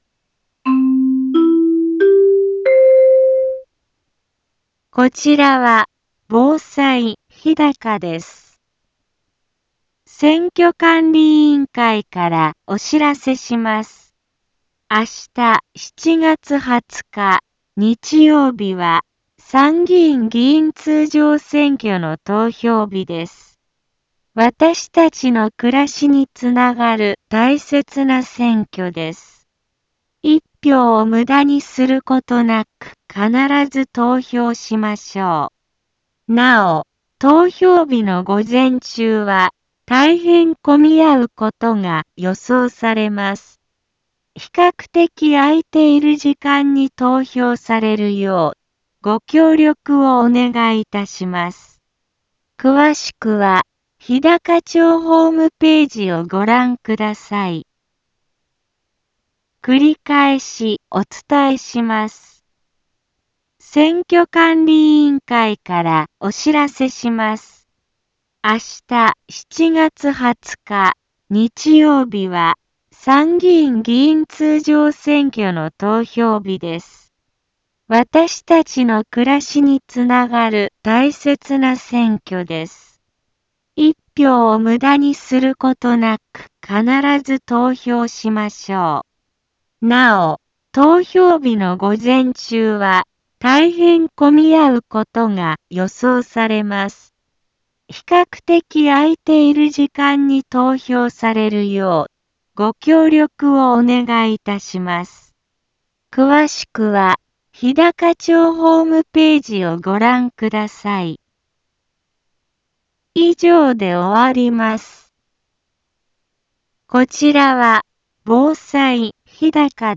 一般放送情報
Back Home 一般放送情報 音声放送 再生 一般放送情報 登録日時：2025-07-19 10:04:24 タイトル：参議院議員通常選挙投票棄権防止の呼びかけ インフォメーション： こちらは、防災日高です。